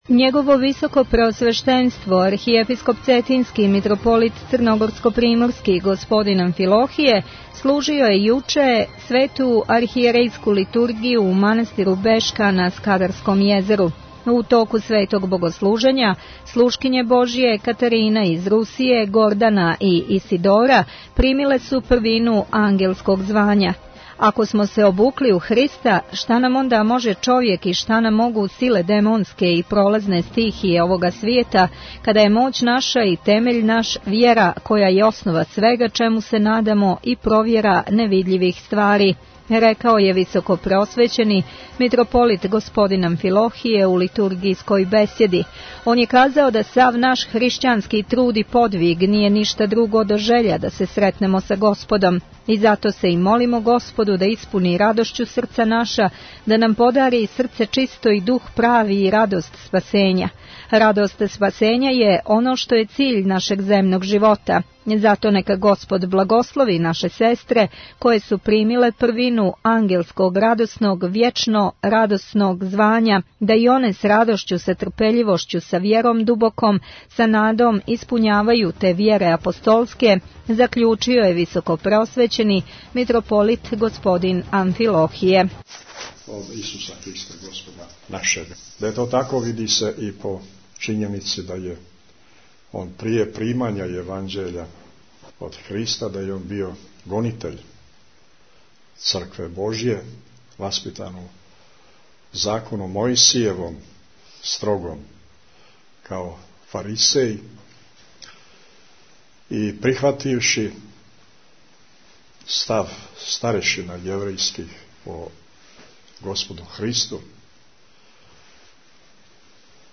Митрополит Амфилохије служио Литургију у манастиру Бешка | Радио Светигора
Tagged: Бесједе Наслов: Mitropolit Amfilohije Албум: Besjede Година: 2014 Величина: 27:24 минута (4.71 МБ) Формат: MP3 Mono 22kHz 24Kbps (CBR) Архиепископ цетињски Митрополит црногорско-приморски Господин Амфилохије служио је јутрос, 12. јануара Свету службу Божију у манастиру Бешка на Скадарском језеру.